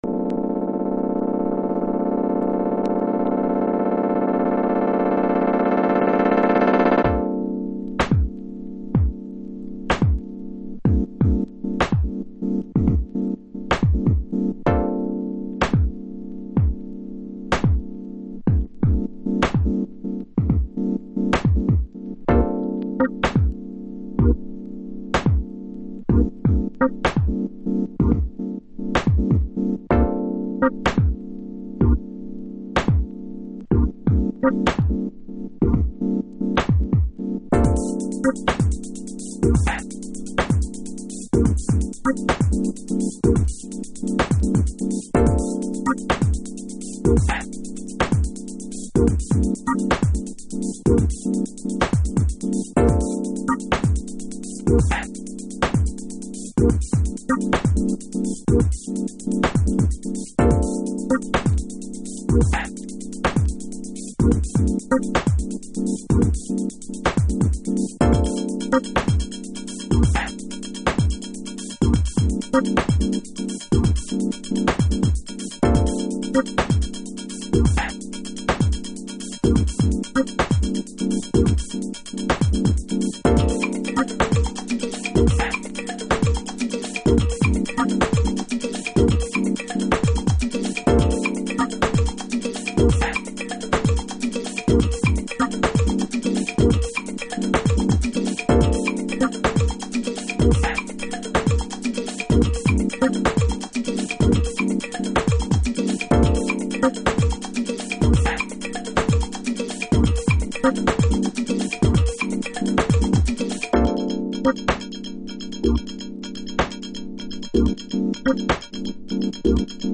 House / Techno
スウィングするドラミングとシンプルなメッセージのリフレインが強く印象的なフロアを作り出します。